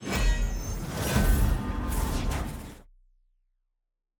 sfx-loot-portal_open_cm_promotion-outro.ogg